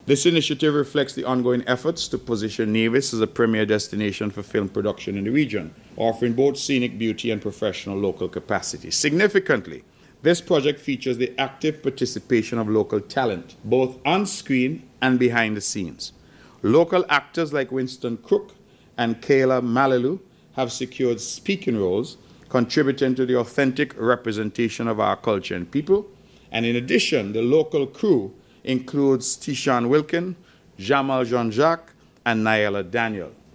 At his most recent press conference, Premier Mark Brantley, Minister of Tourism in the Nevis Island Administration, gave an update on the film industry and spoke about the latest project.